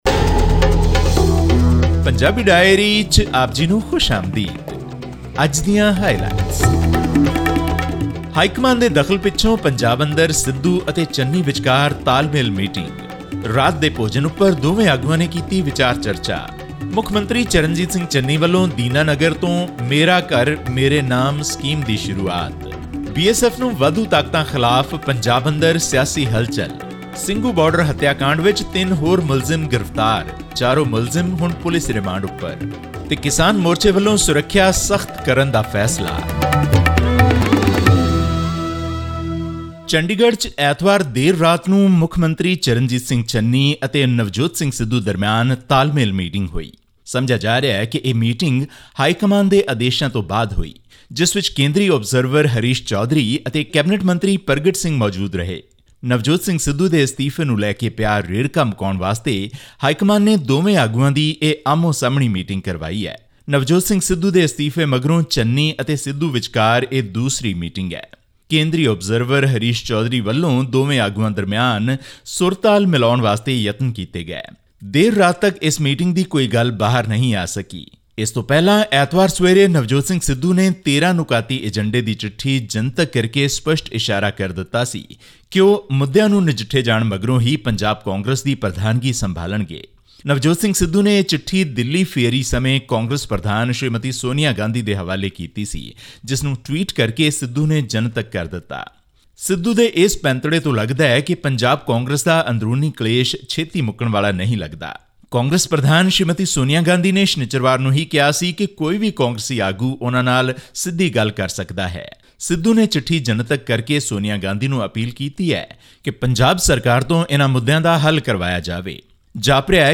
A day after Punjab Congress Chief Navjot Singh Sidhu made public his letter to Sonia Gandhi, chief minister Charanjit Singh Channi met Mr Sidhu in Chandigarh on 17 October to iron out issues that have become a bone of contention between the two leaders. This and more in our weekly news update from Punjab.